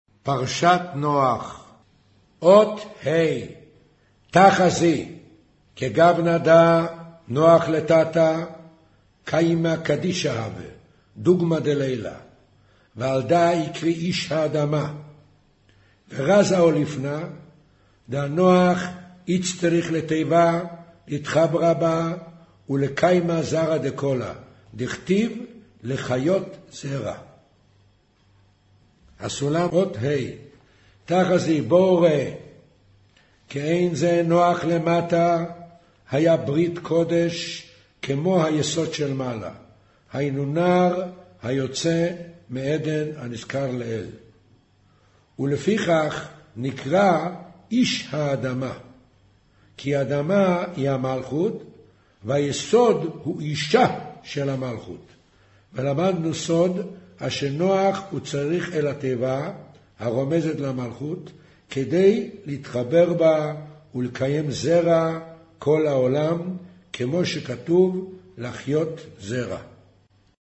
קריינות זהר, פרשת נח, מאמר נח ותיבה אות ה'